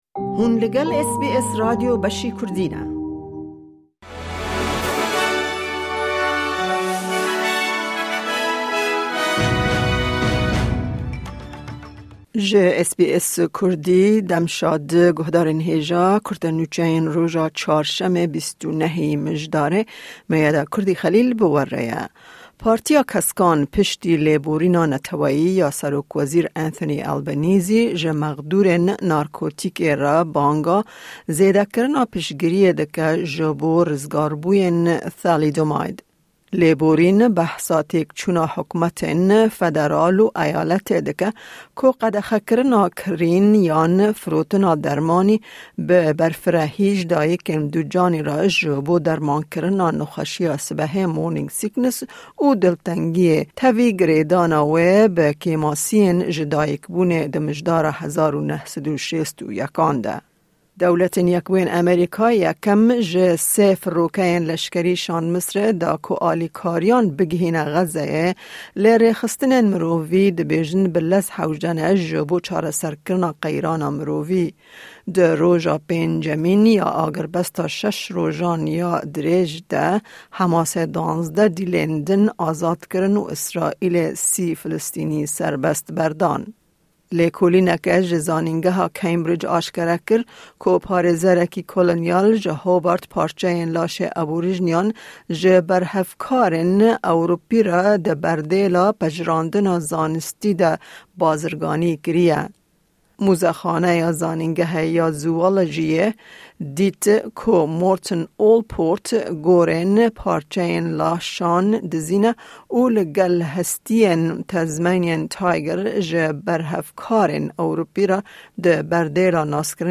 Kurte Nûçeyên roja Çarşemê 29î Mijdara 2023
Newsflash